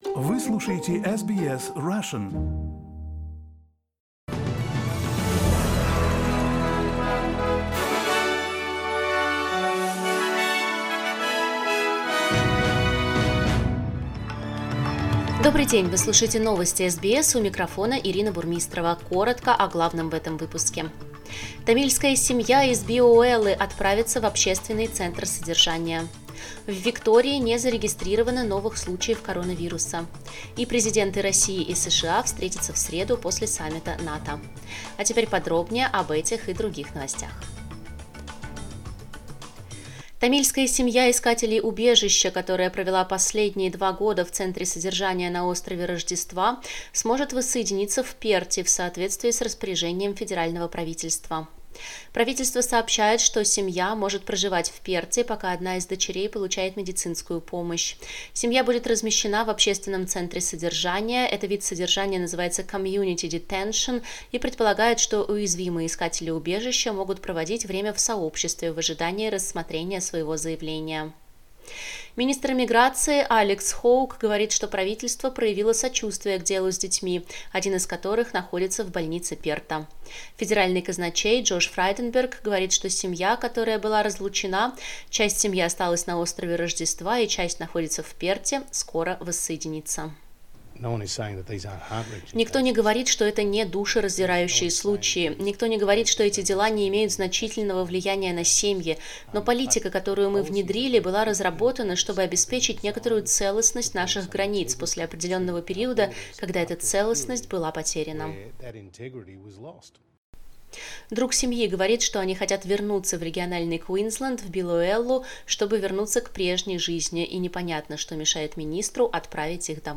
Новости SBS на русском языке - 15.06